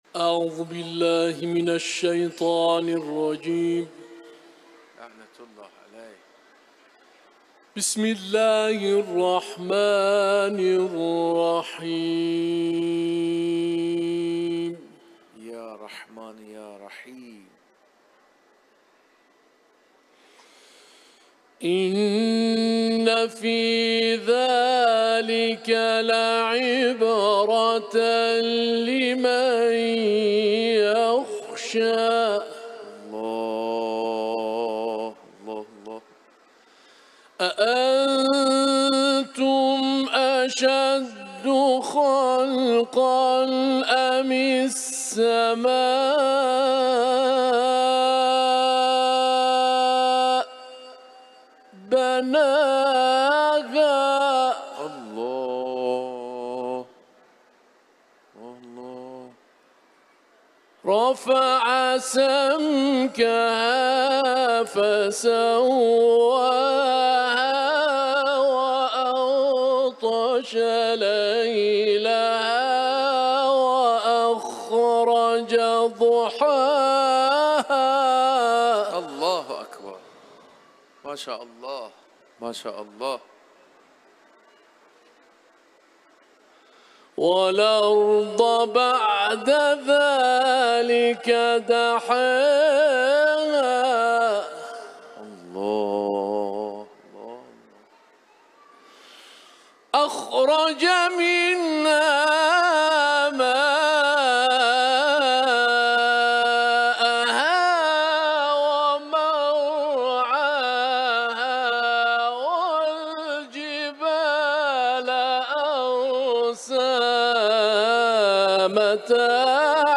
IQNA – İranlı Uluslararası Kur’an kârisi Naziat suresinden ayetler tilavet etti.